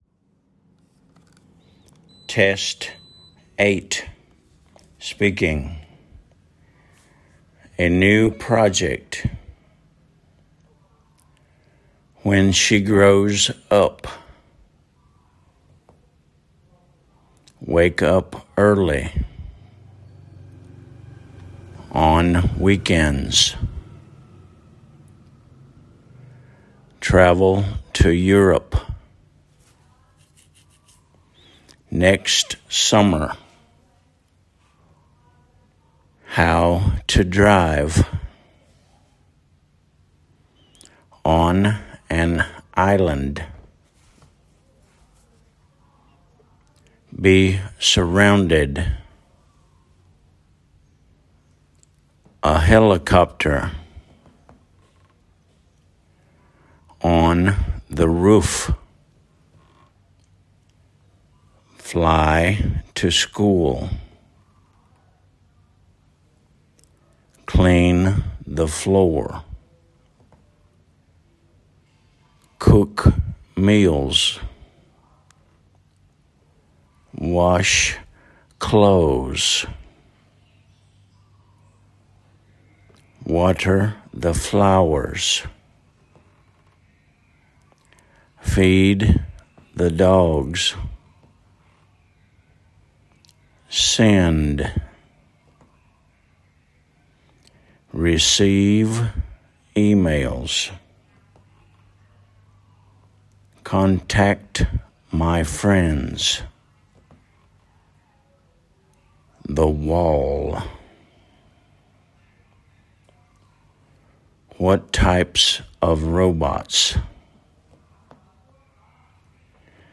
a new project /ə njuː ˈprɒdʒɛkt/
wake up early /weɪk ʌp ˈɜːli/
a helicopter /ə ˈhɛlɪkɒptə/